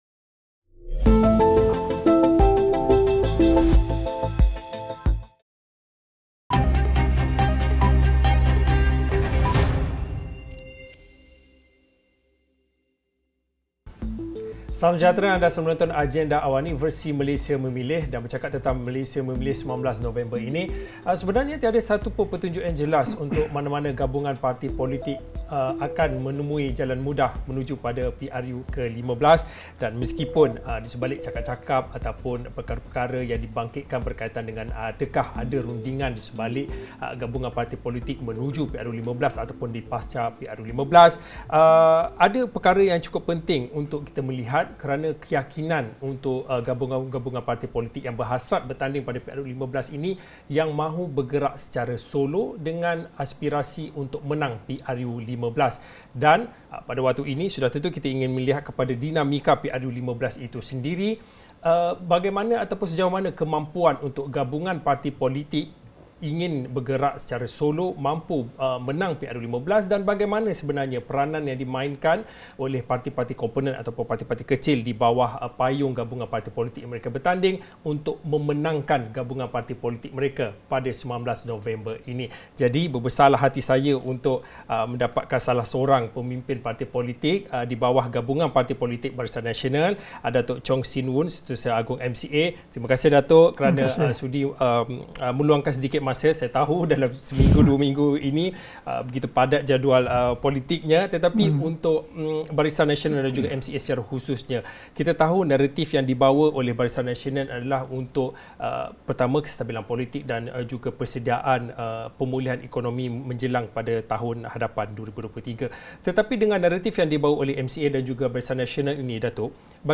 Menjadi persoalan utama bagi parti-parti bertanding menuju gelombang PRU15 sudah tentu melihat kepada apa strategi ‘misi untuk menang’ sokongan pengundi dengan jangkaan pertembungan sengit di beberapa kerusi tumpuan. Diskusi 8.30 malam dan 9 malam.